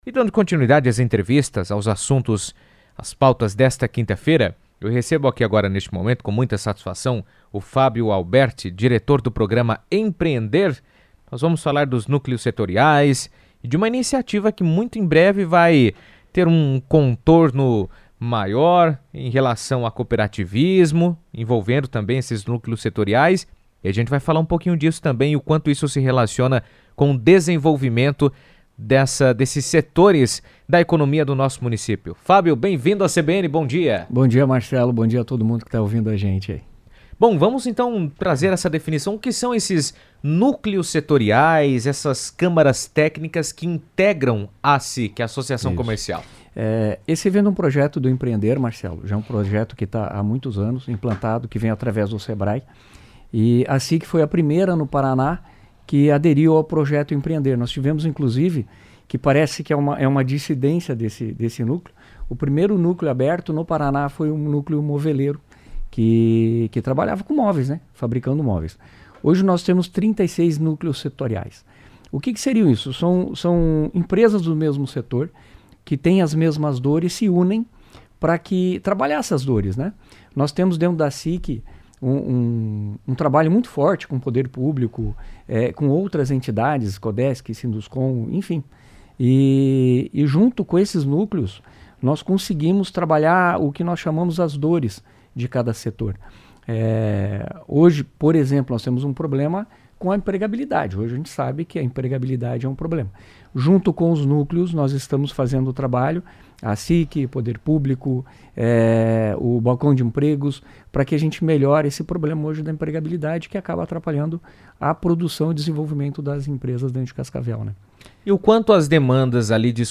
Os núcleos setoriais do Programa Empreender têm se consolidado como espaços estratégicos para que empresários pensem soluções coletivas e fortaleçam seus negócios. Com o apoio da ACIC, os grupos compartilham experiências, identificam desafios em comum e buscam alternativas inovadoras para o crescimento do setor. Em entrevista à CBN Cascavel